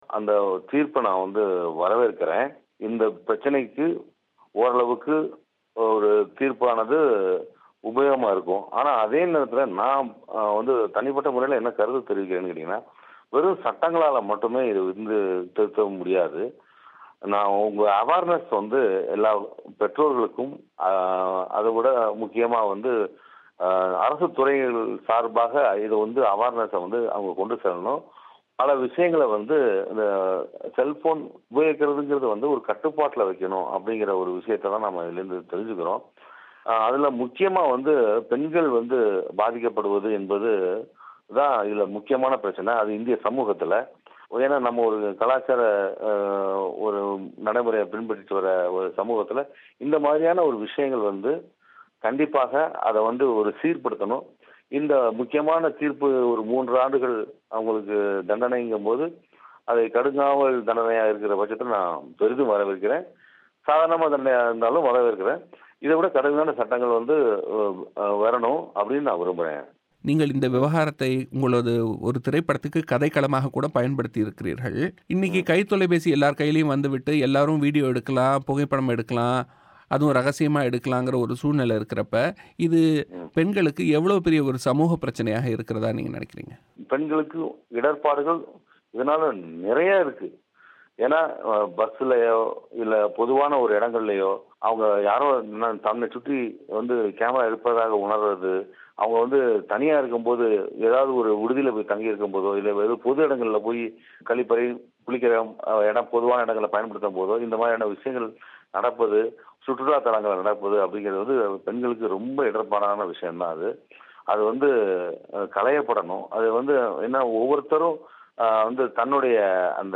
காதலியோடு நெருங்கியிருந்ததை ரகசியமாக படம்பிடித்தவருக்கு சிறைத் தண்டனை விதிக்கப்பட்டிருப்பது பற்றி இவ்விவகாரத்தை தனது படத்தில் கதைக்களமாக கையாண்டிருந்த இயக்குநர் பாலாஜி சக்திவேல் தமிழோசைக்கு அளித்த பேட்டி.